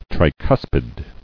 [tri·cus·pid]